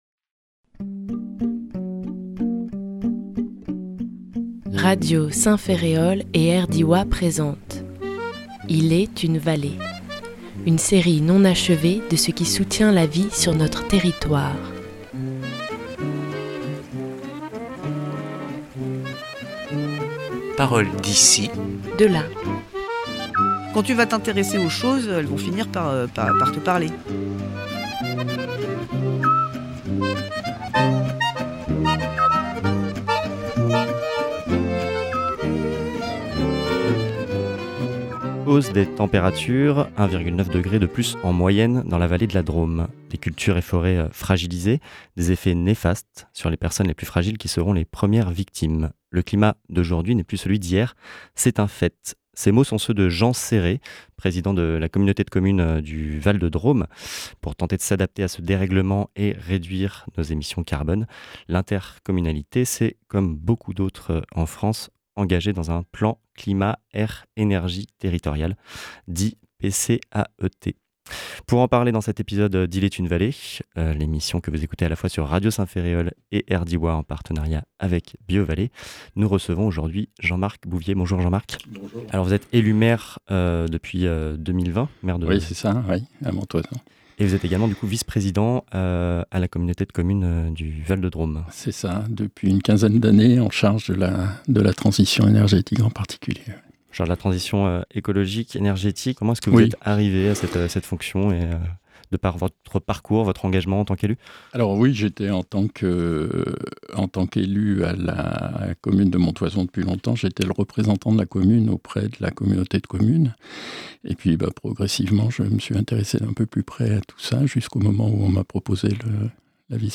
Pour tenter de s’attaquer aux principales causes de ce dérèglement et s’adapter à ses conséquences, l’intercommunalité s’est engagée dans un Plan Climat Air Energie Territorial (PCAET). Pour en parler, nous avons reçu Jean-Marc Bouvier, maire de Montoison depuis 2020 et vice-président à la CCVD sur la compétence Transition écologique.